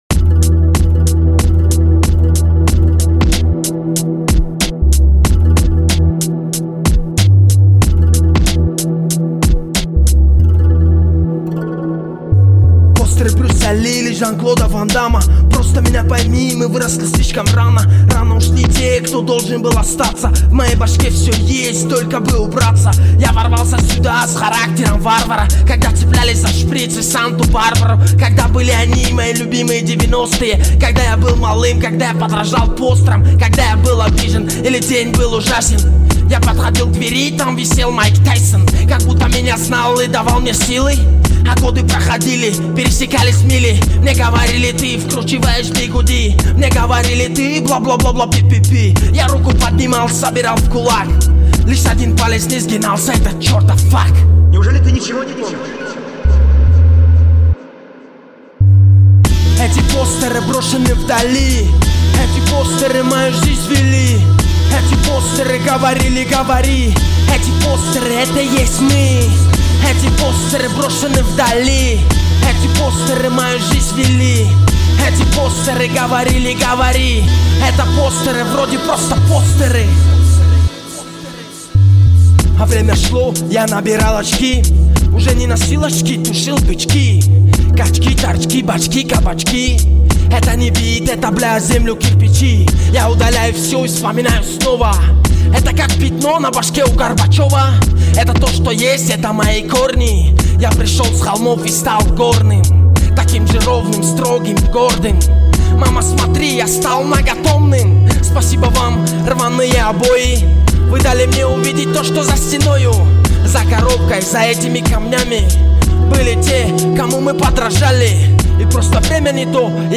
Категория: Тадж. Rap